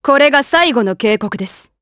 Glenwys-Vox_Skill3_jp.wav